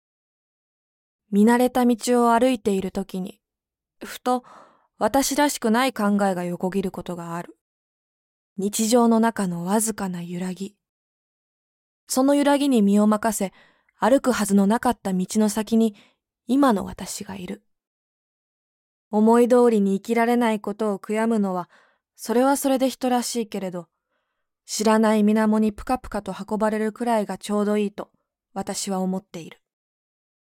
ボイスサンプル
独白